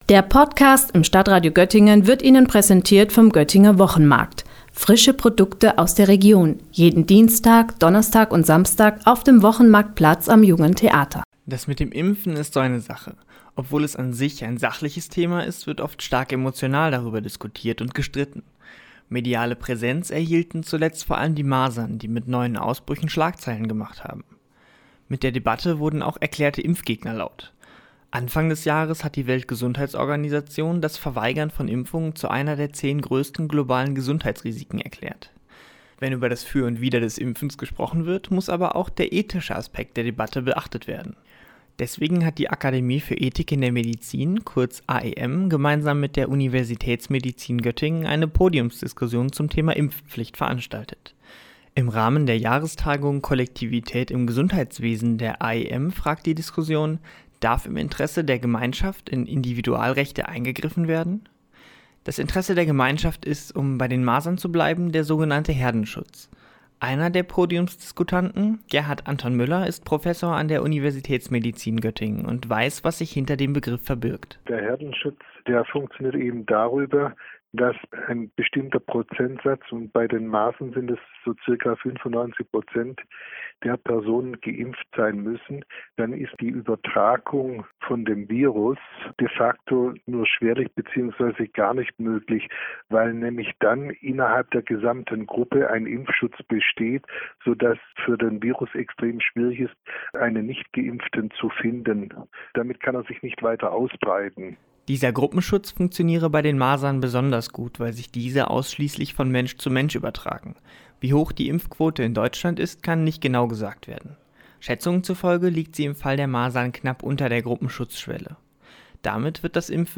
Dazu hat eine Podiumsdiskussion mit dem Titel „Impfpflicht als Dilemma“ Gemeinschaftsinteressen und Individualrechte in die Waagschale geworfen.